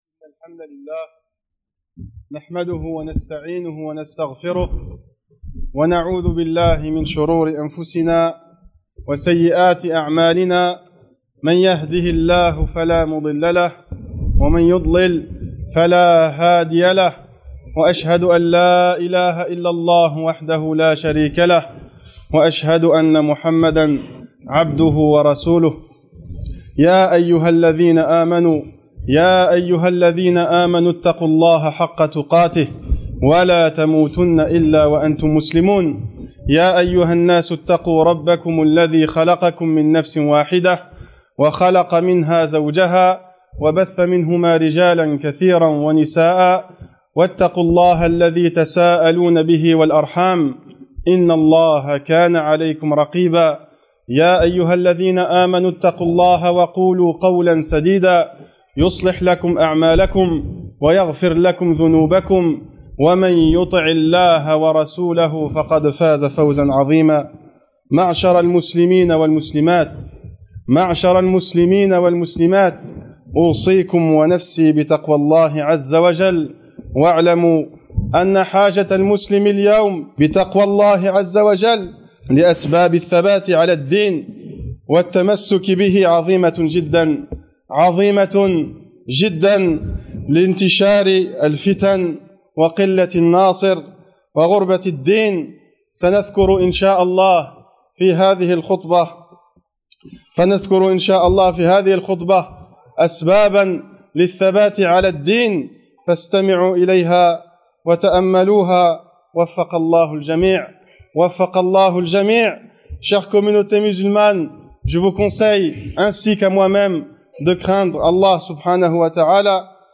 Ce sermon montre comment la personne doit faire pour rester constante dans la foi et l’adoration.